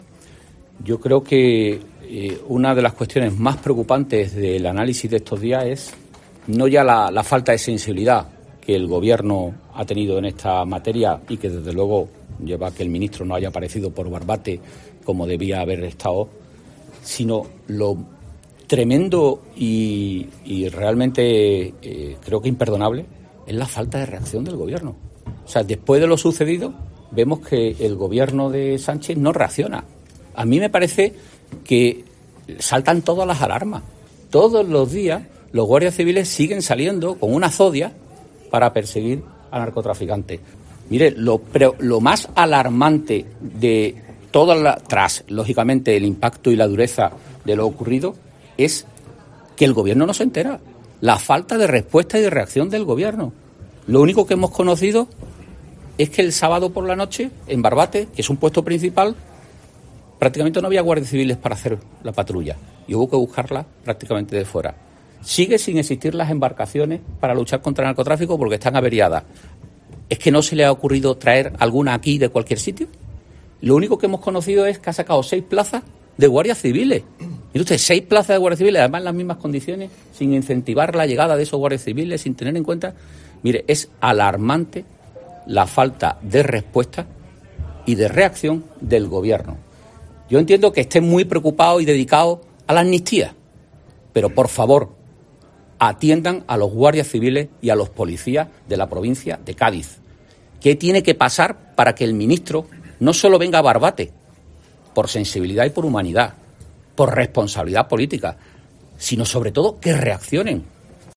Antonio Sanz, consejero de presidencia de la Junta de Andalucía sobre el asesinato en Bartate